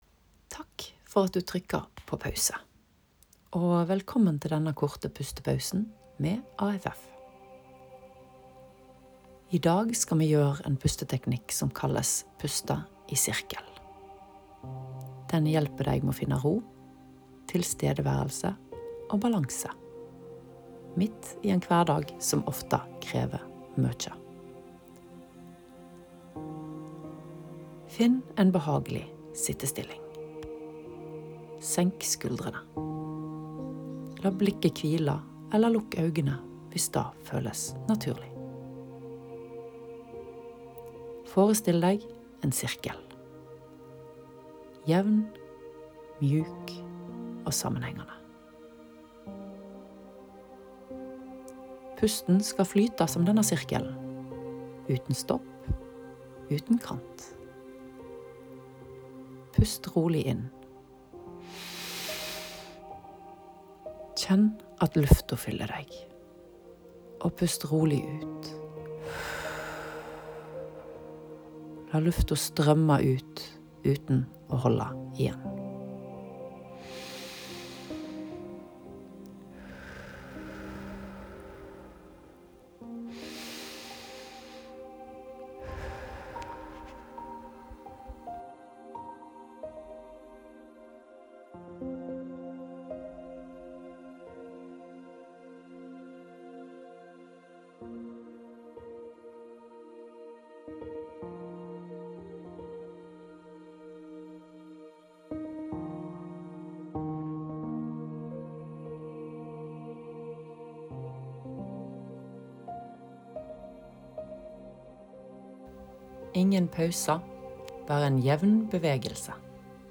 Her får du korte, konkrete øvelser for pust, avspenning og tilstedeværelse – uten mystikk, bare effekt.